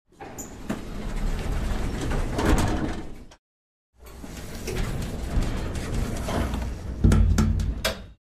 elevator_open_close.mp3